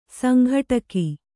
♪ sanghaṭaka